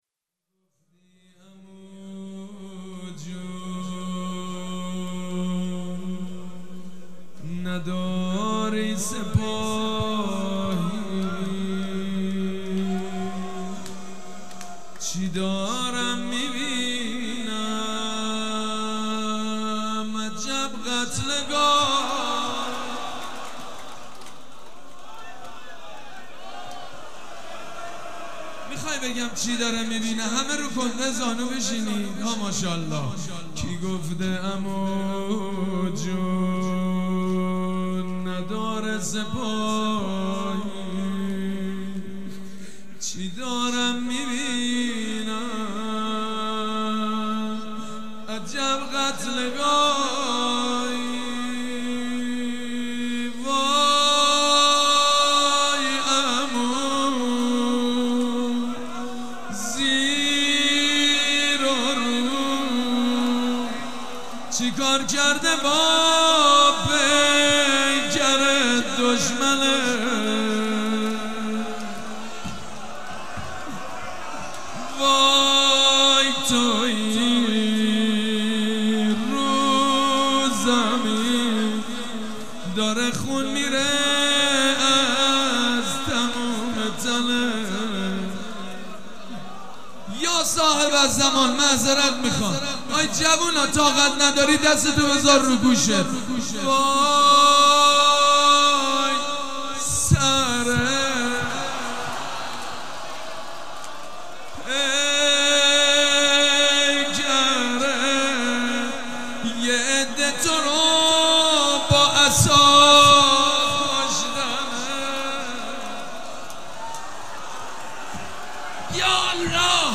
دانلود مداحی و روضه خوانی شب پنجم ماه محرم در سال 1396
بخش اول – روضه